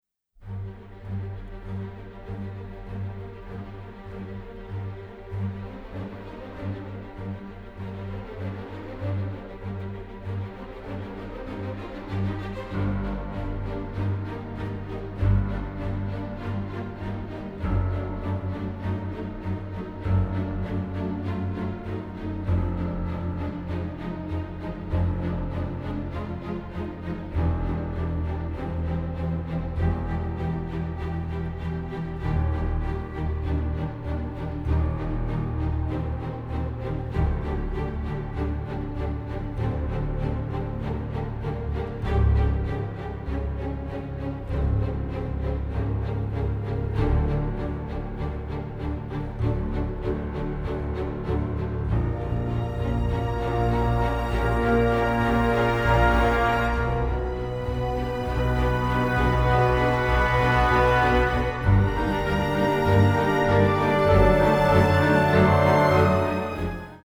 an energetic, dark and crude orchestral score